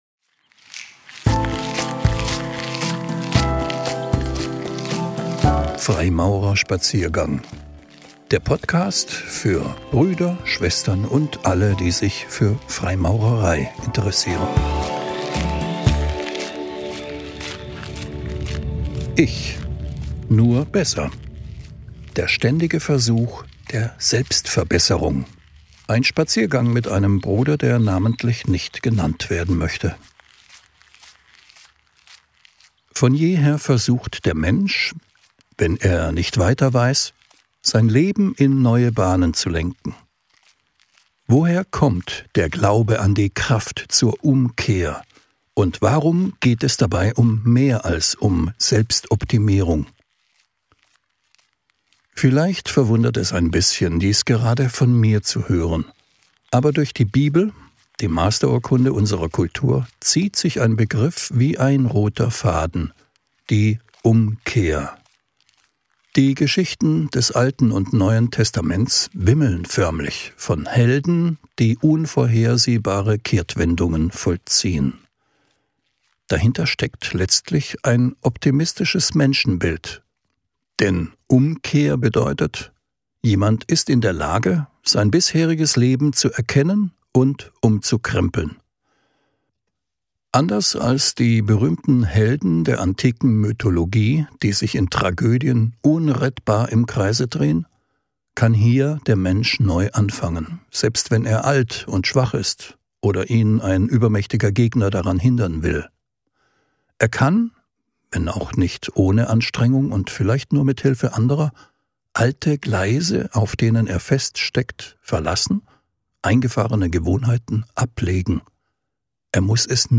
Ein Spaziergang mit einem anonymen Freimaurer-Bruder.